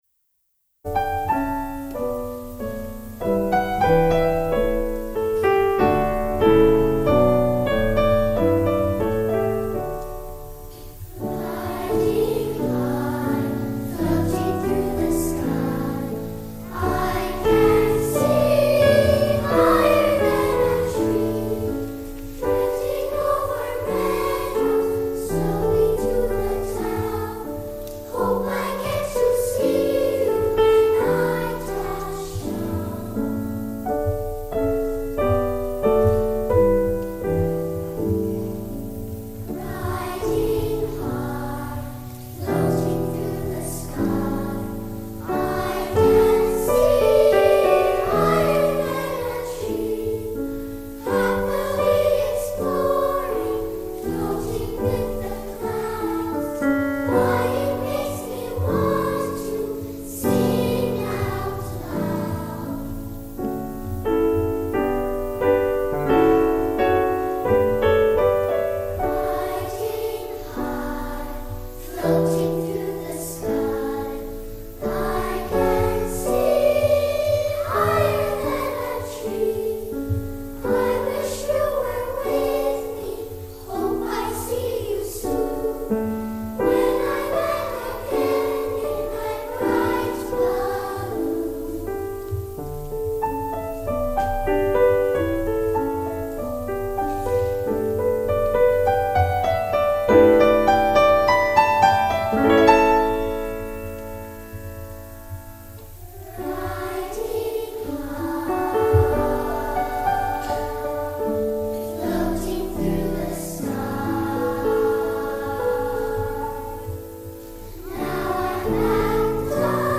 Genre-Style-Forme : Profane ; Enfants
SS OU SA (2 voix égales de tous petits )
Flûte et piano
Tonalité : ré majeur